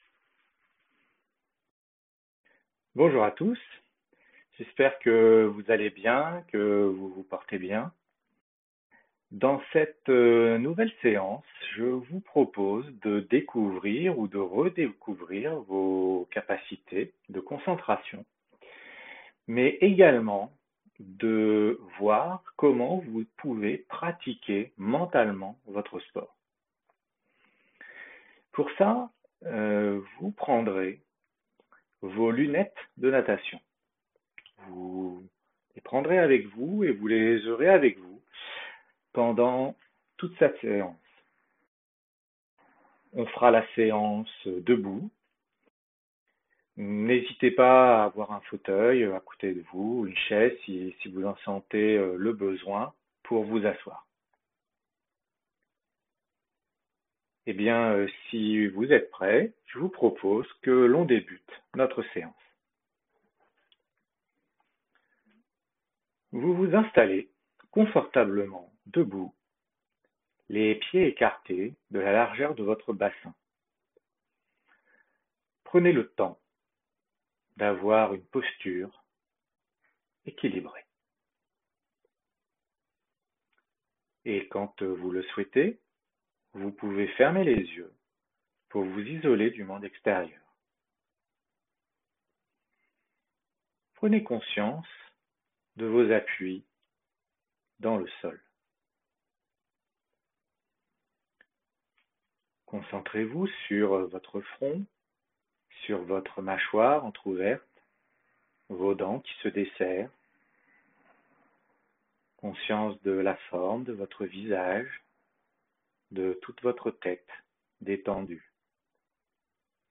Ces petites séances de sophrologie ont été enregistrées pendant la première période de confinement COVID19.